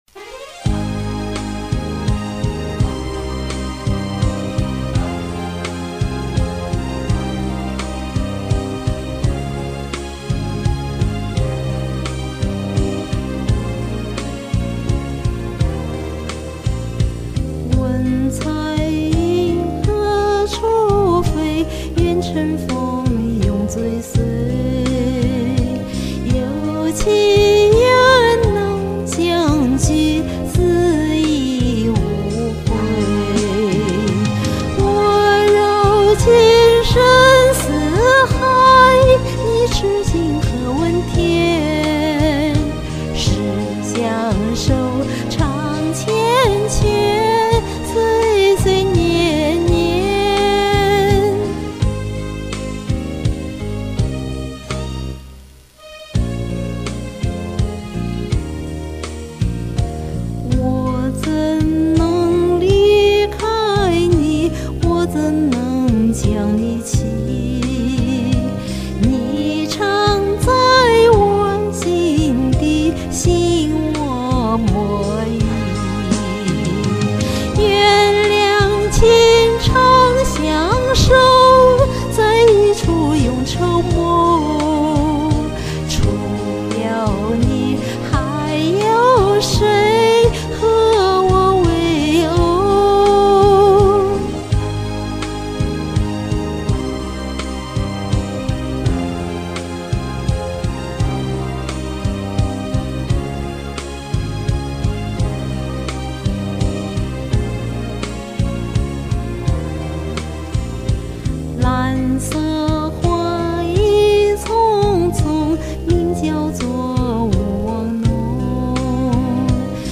你可别用我这版伴奏哈，杂音大！